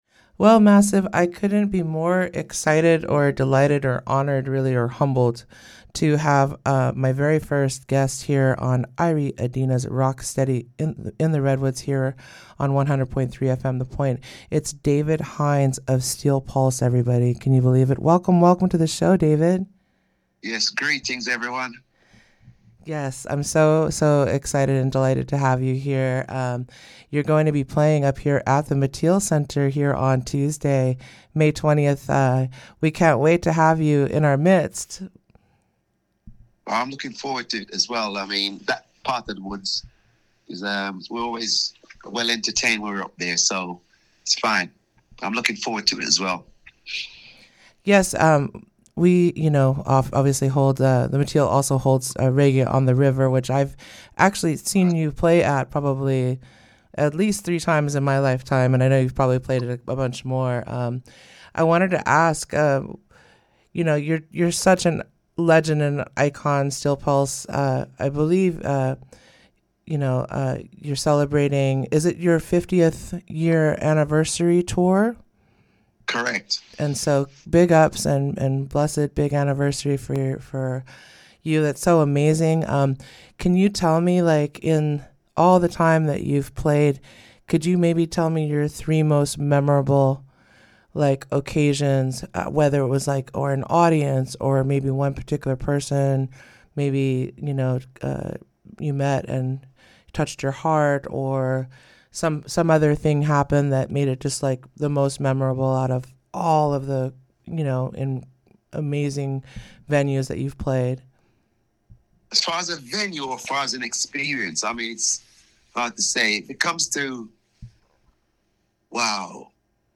In the interview above, he looks back on five decades with Steel Pulse, talks about touring with Bob Marley, reveals who he’d want to direct a Steel Pulse biopic, and shares what’s on his current playlist.